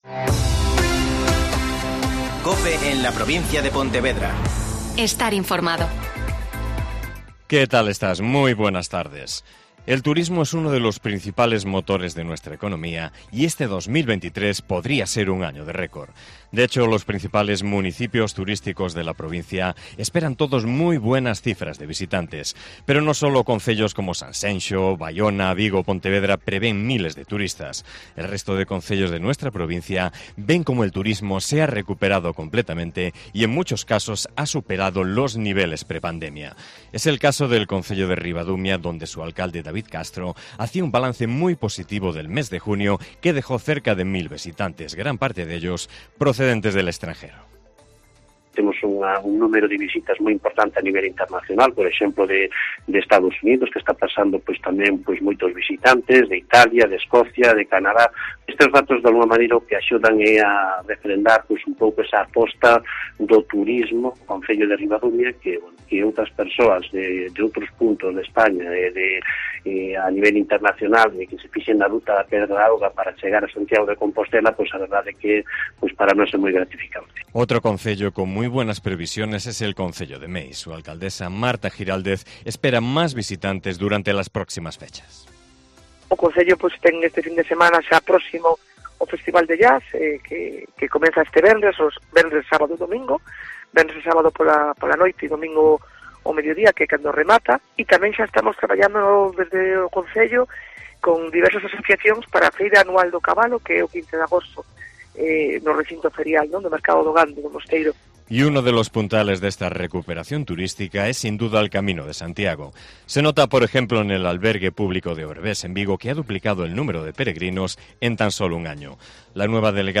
Mediodía COPE Provincia de Pontevedra (Informativo 14:20h)